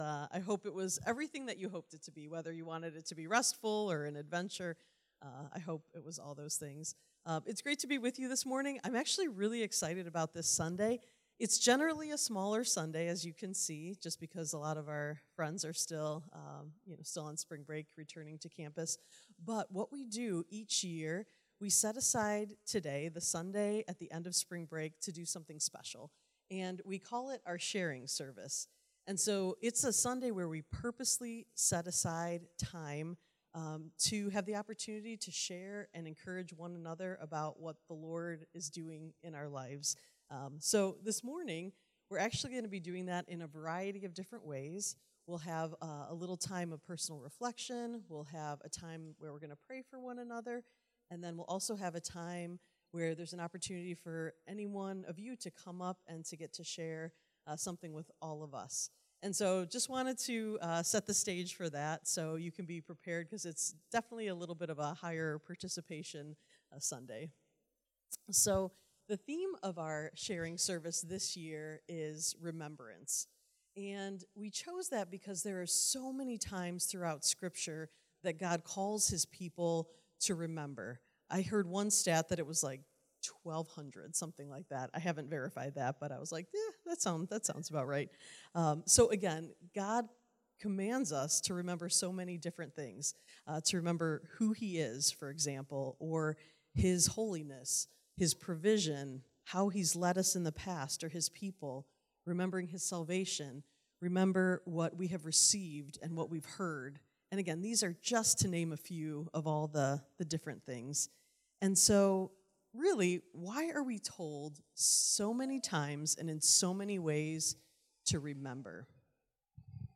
March 22, 2026 | Sharing Service